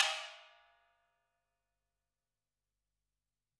描述：大金属罐，用鼓棒的各种敲击声用一个EV RE20和两个压缩的omni麦克风录制的立体声大量的房间声音
Tag: 大锡 工业 金属 金属